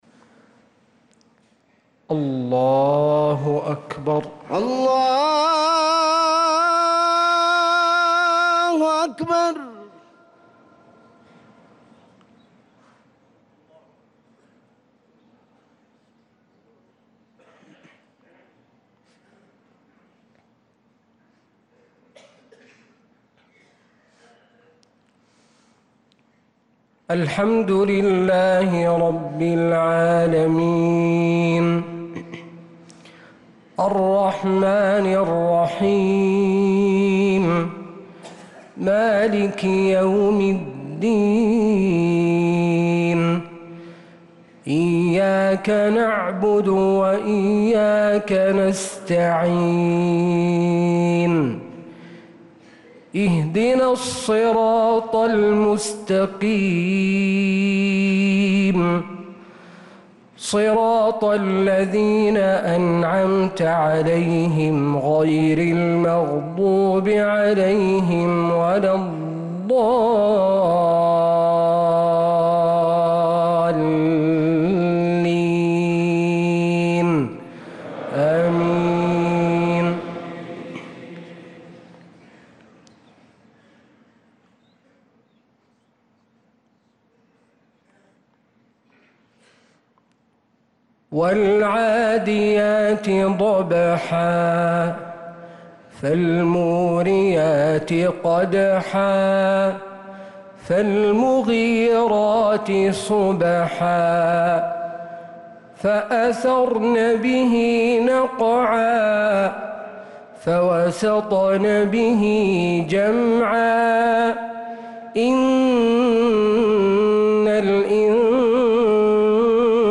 صلاة المغرب